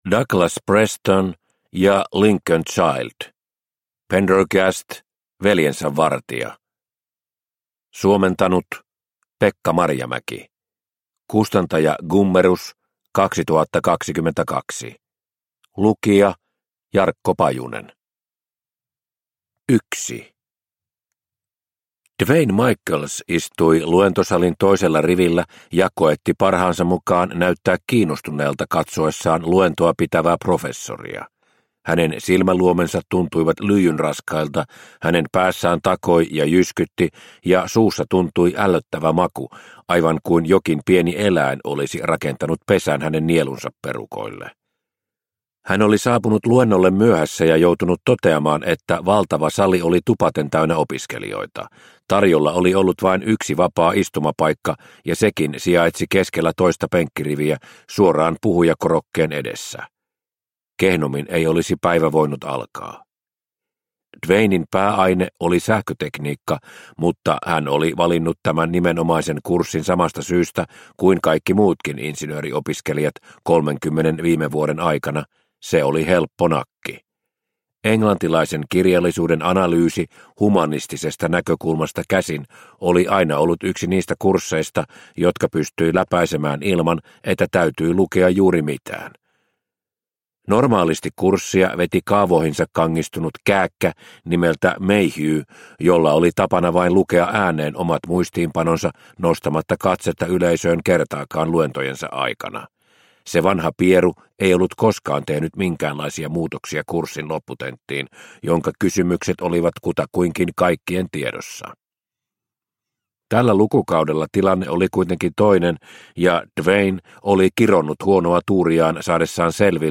Veljensä vartija – Ljudbok – Laddas ner